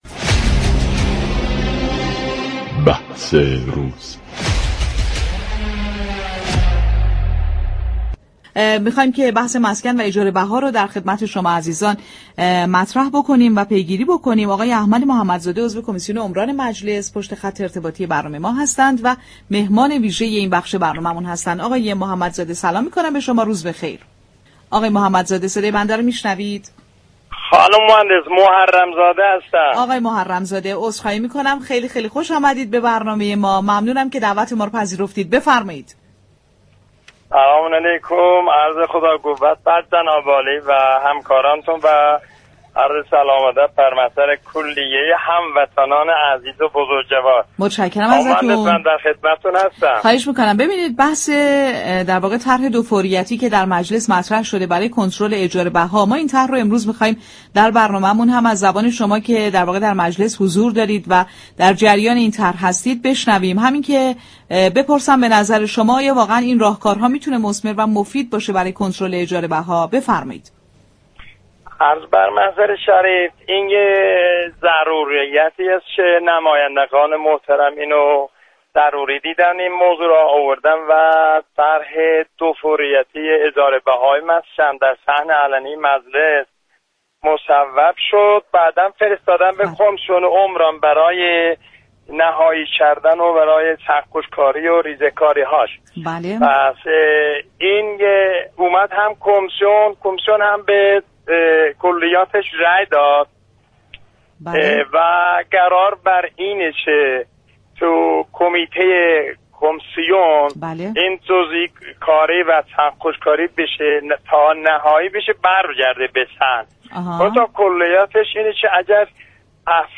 میهمان‌های تلفنی این برنامه بودند.